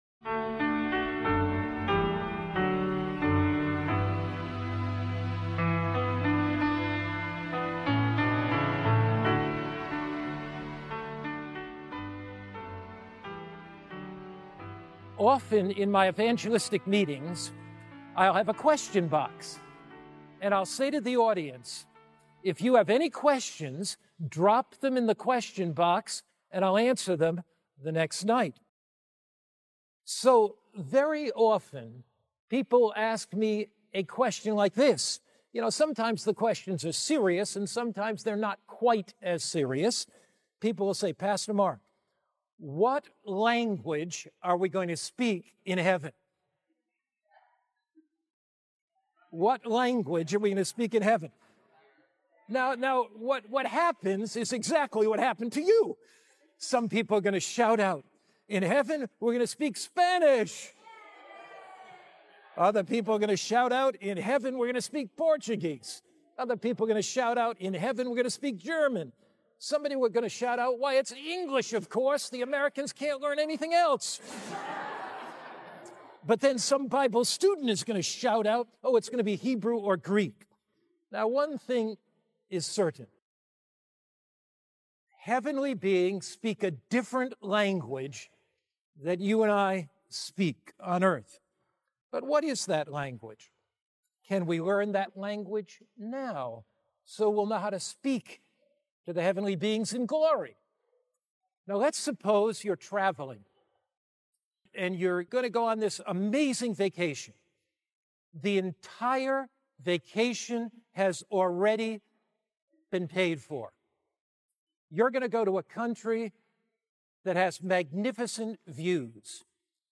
This sermon reveals how modern neuroscience affirms the biblical call to renew the mind, showing that Scripture, prayer, and service can literally reshape the brain through neuroplasticity. Blending faith and science, it offers a hopeful, practical path to lasting mental and spiritual transformation grounded in God’s promises.